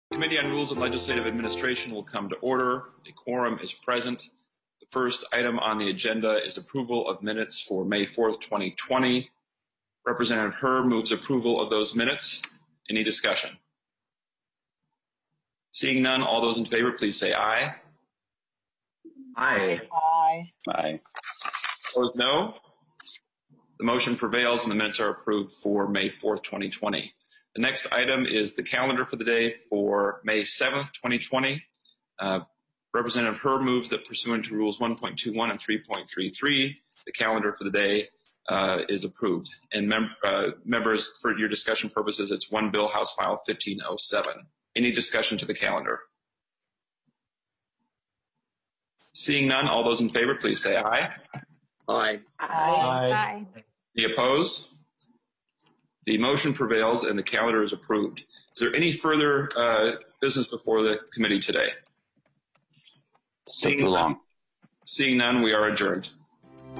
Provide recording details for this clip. Remote Hearing